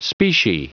Prononciation du mot specie en anglais (fichier audio)
Prononciation du mot : specie